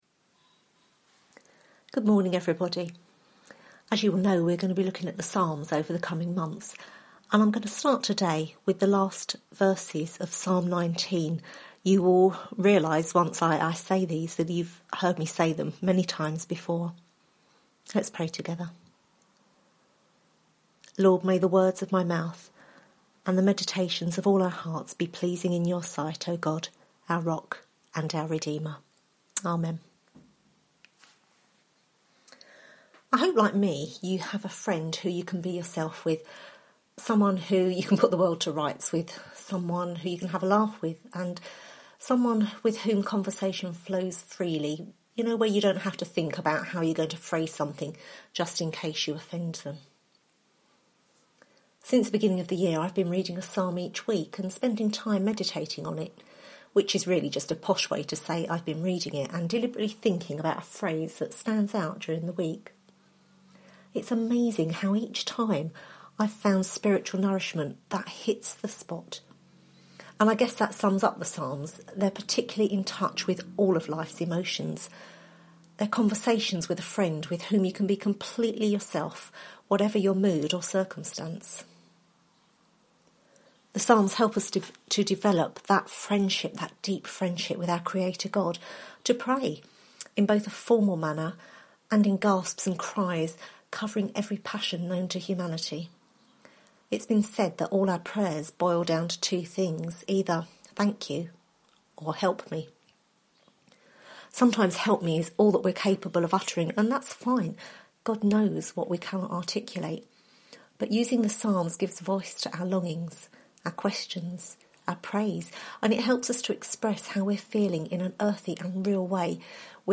Psalms Service Type: Sunday Morning « Cultivating Spiritual Practices